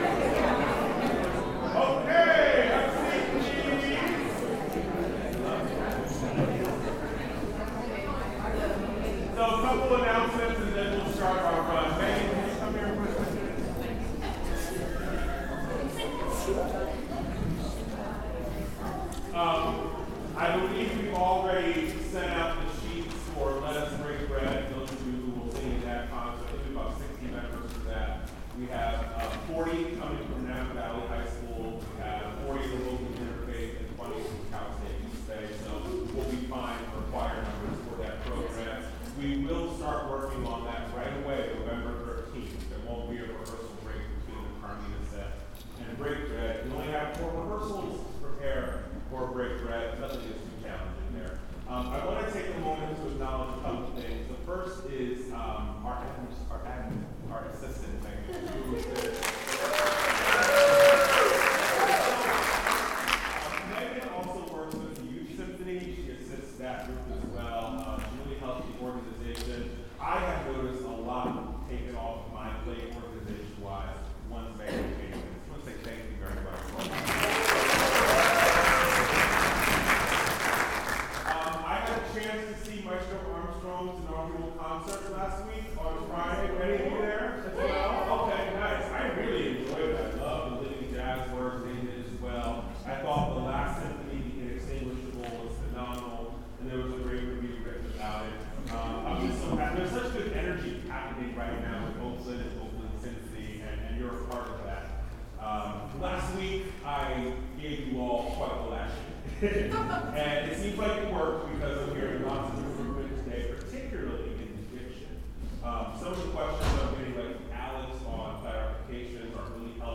OSC Rehearsal, Wednesday, October 23, 2024 - part 2